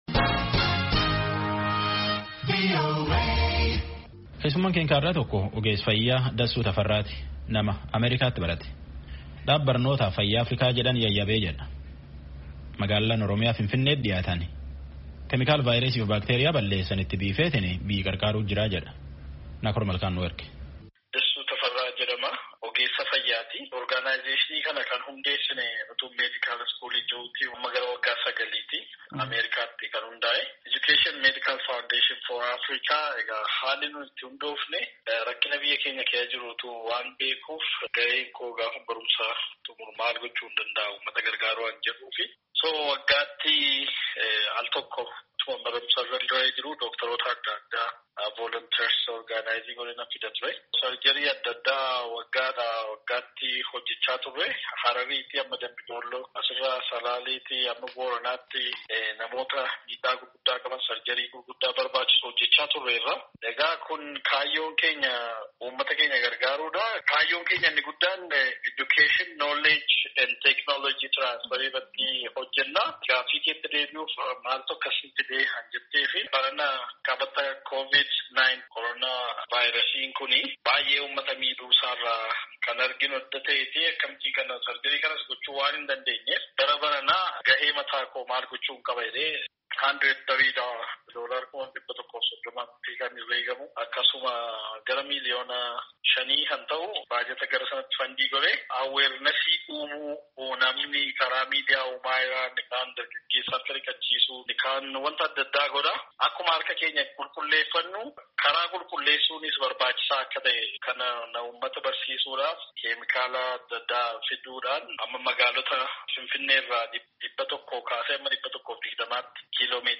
telefoonaan dubbise.